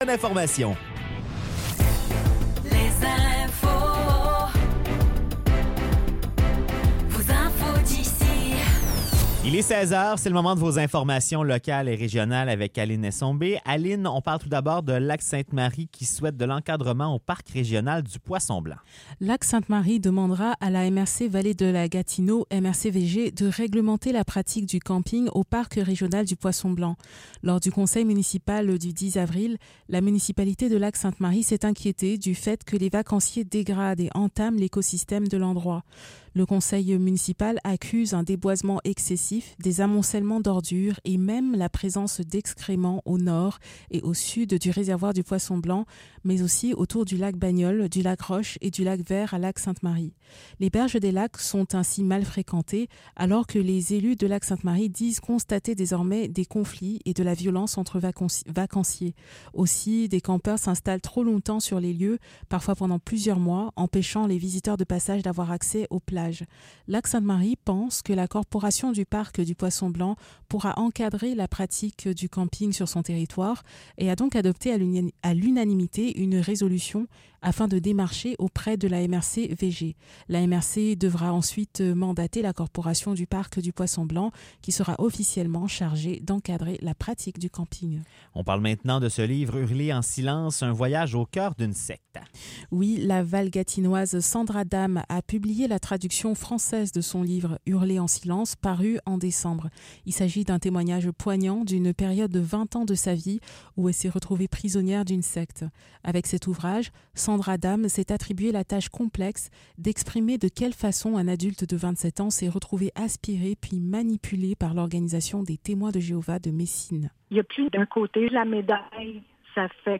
Nouvelles locales - 1er mai 2024 - 16 h